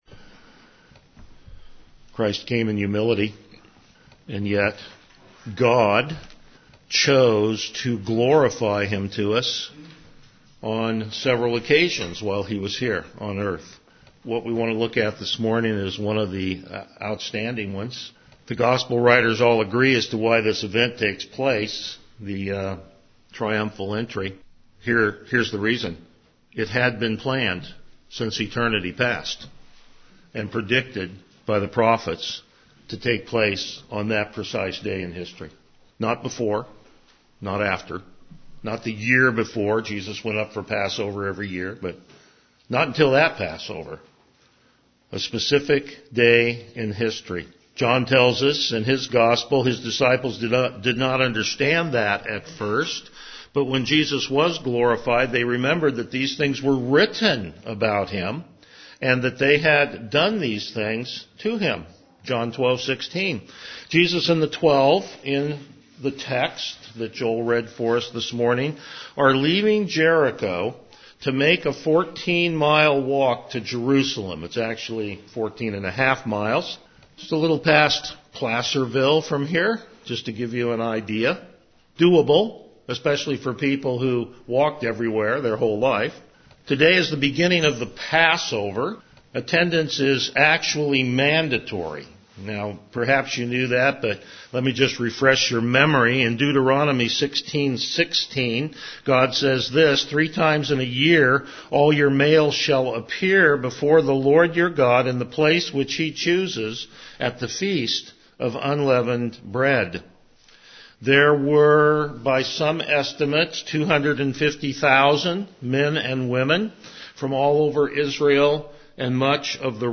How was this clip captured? Selected Passages Service Type: Morning Worship Palm Sunday Message Topics: Topical Sermons